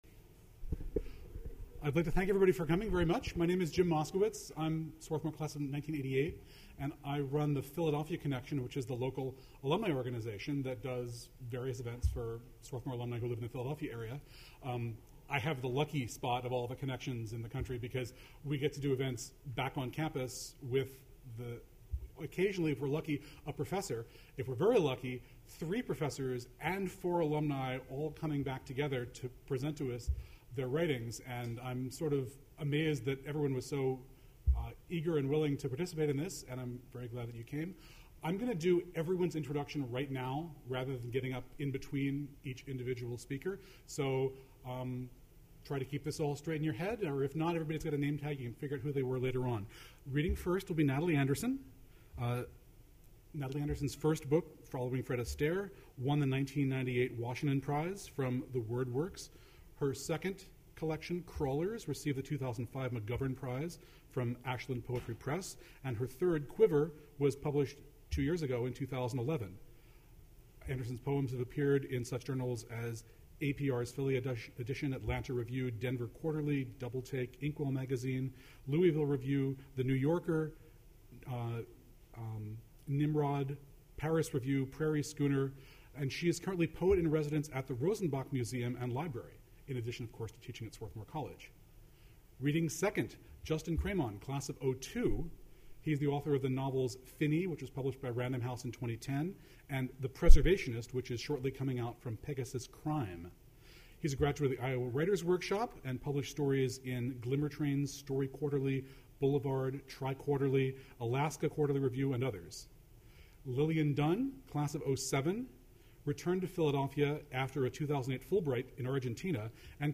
Alumni and Faculty Reading
alumni-and-faculty-reading.mp3